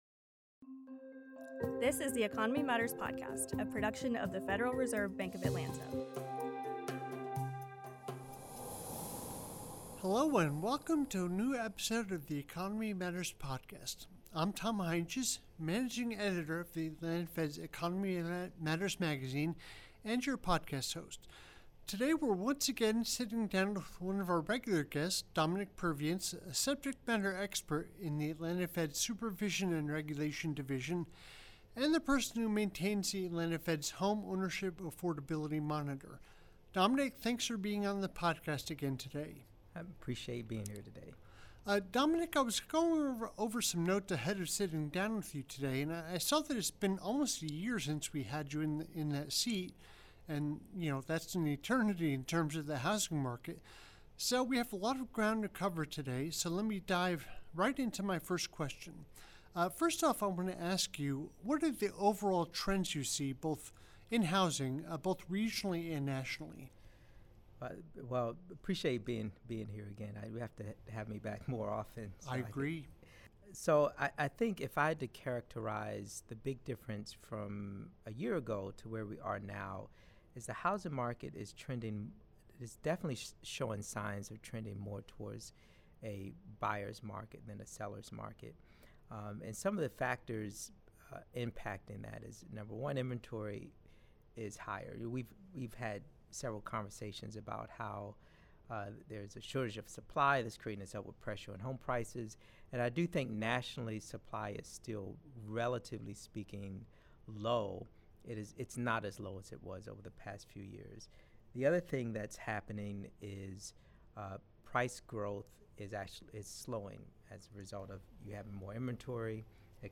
This episode of the Economy Matters podcast features a discussion of residential real estate.